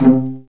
PIZZ VLN C2.wav